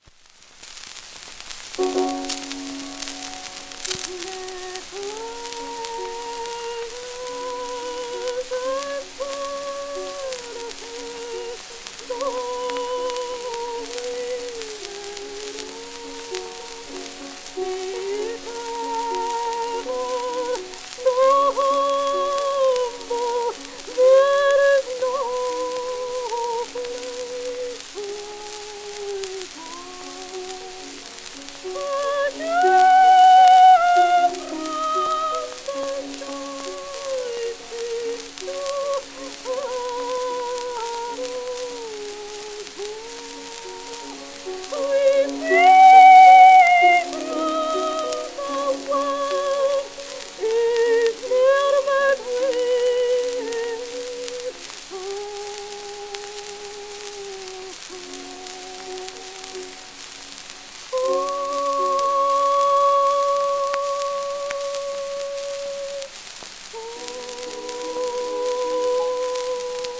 12インチ 片面盤
1905年録音
旧 旧吹込みの略、電気録音以前の機械式録音盤（ラッパ吹込み）